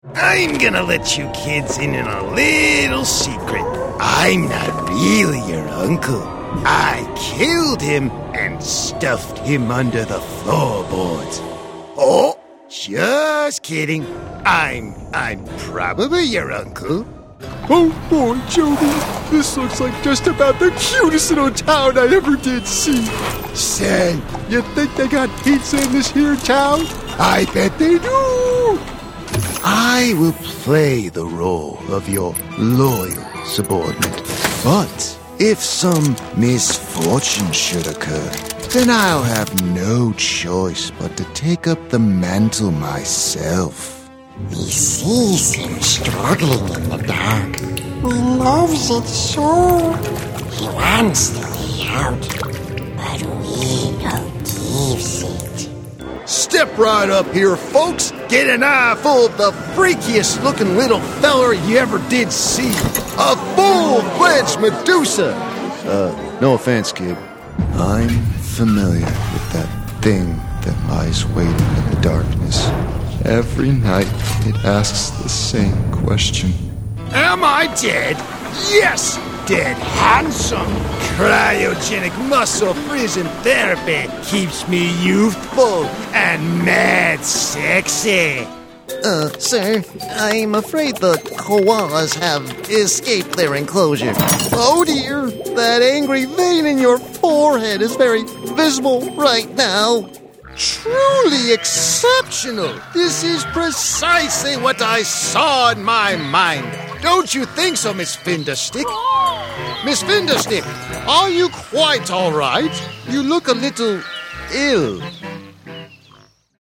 Animation Demo Reel
Young Adult
Character Voice